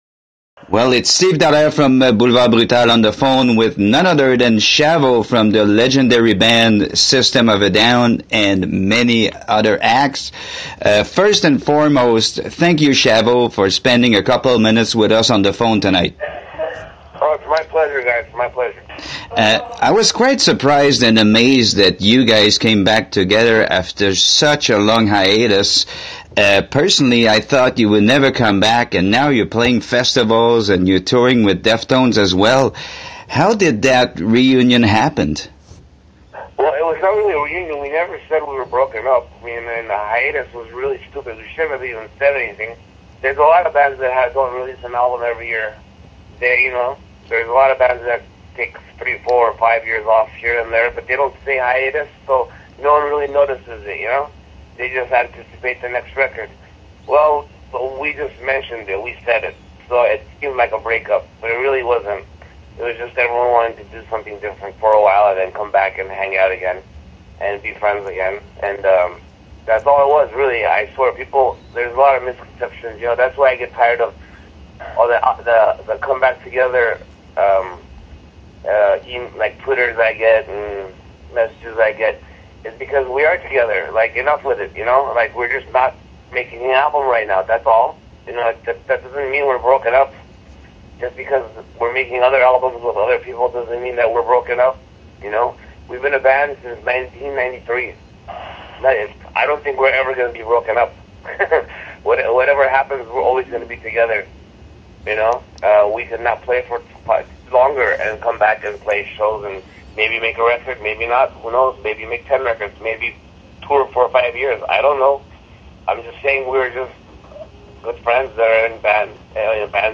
À 10:15, plus rien, que de la musique…